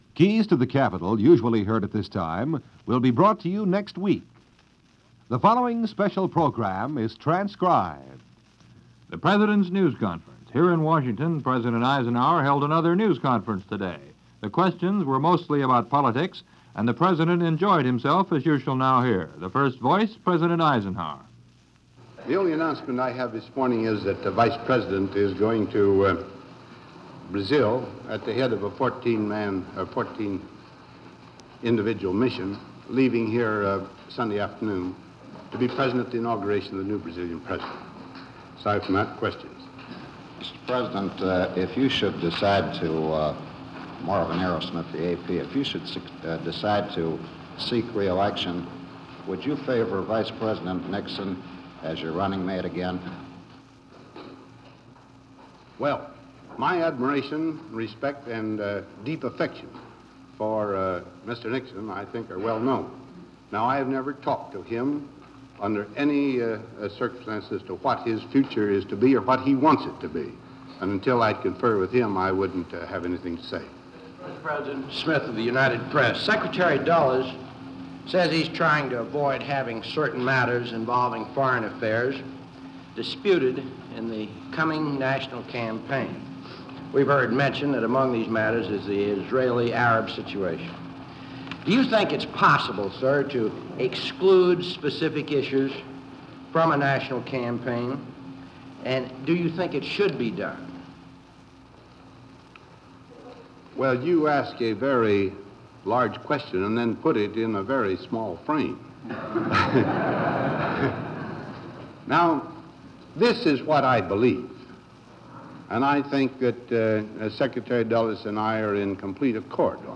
January 25, 1956 – 60 years ago today, President Eisenhower faced a barrage of Journalists inquiries as part of his regular routine of keeping the Press informed about the goings on at the White House.